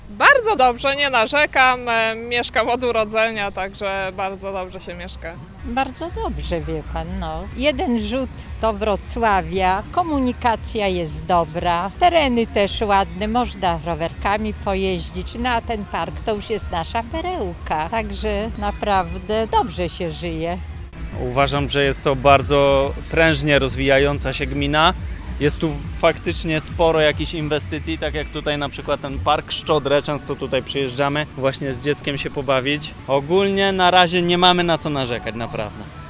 Przy okazji święta Gminy Długołęka zapytaliśmy jej mieszkańców, jak oceniają życie na terenie gminy.